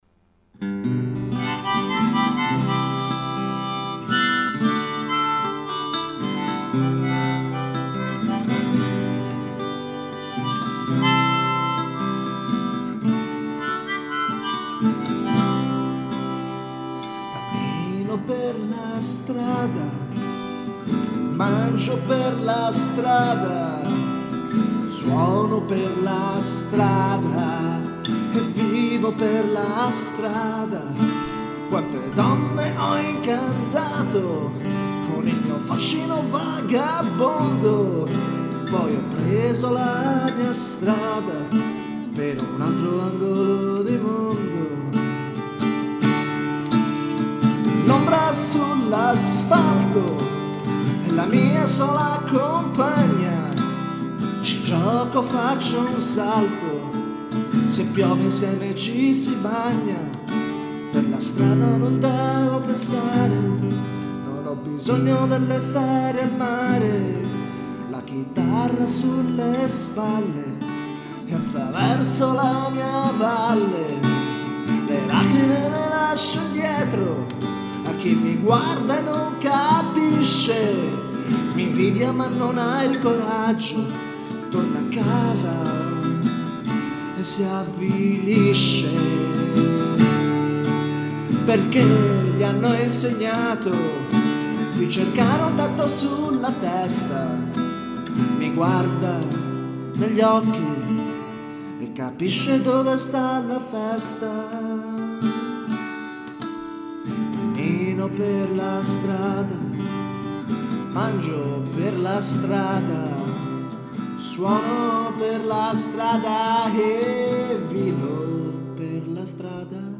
Bella malinconica!